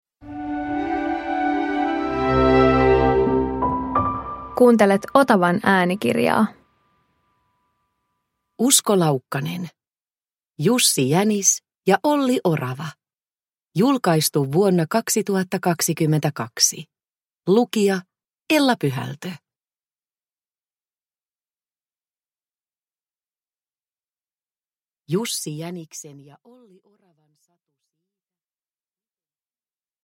Jussi Jänis ja Olli Orava – Ljudbok – Laddas ner